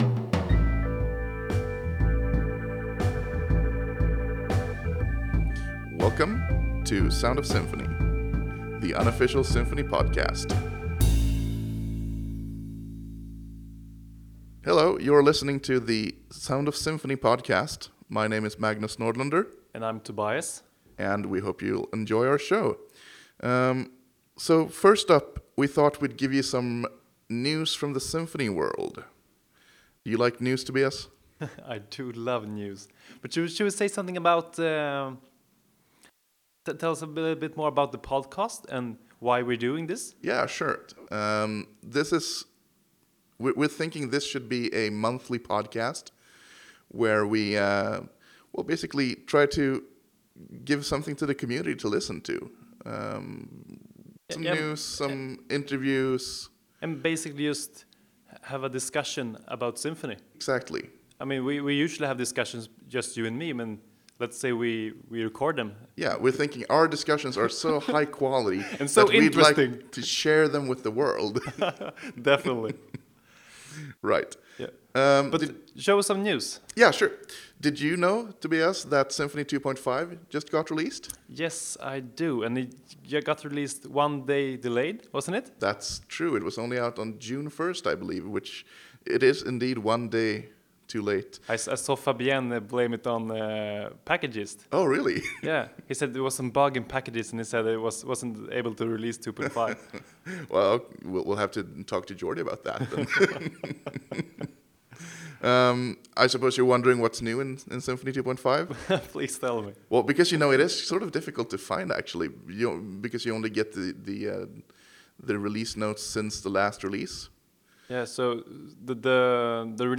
Of course, since this is our first episode, it has some mandatory audio production mistakes, we're sorry about those. Nothing too horrible, you might not even hear it, but we'll always know it's there, and we'll make sure to do better next month!